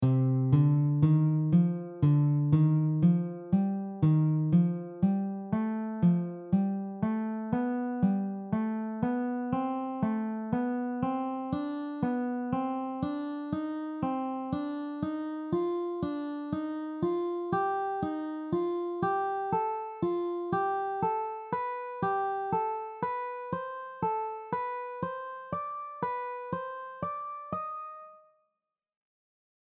The following patterns are all based on the C melodic minor scale.
4 note ascending scale pattern
The following pattern ascends four consecutive notes in the scale and repeats on the next scale degree.